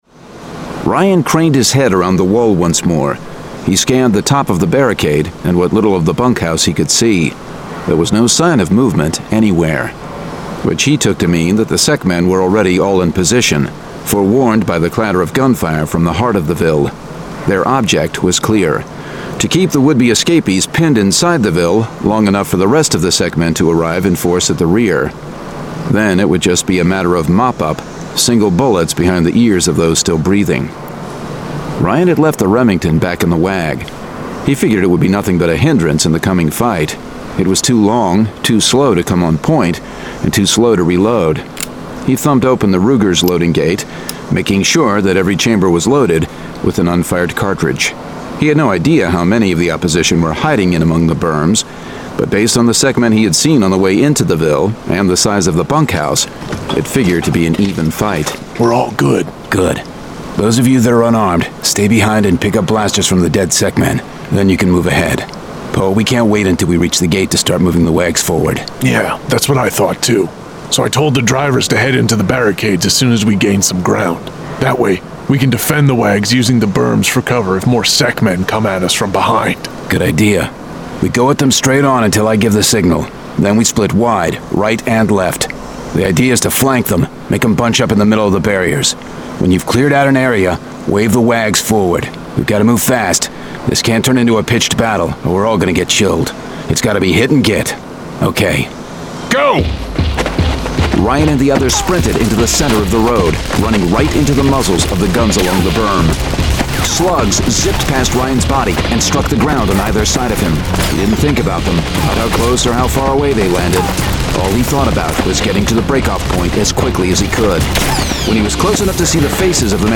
Full Cast. Cinematic Music. Sound Effects.
[Dramatized Adaptation]
Genre: Science Fiction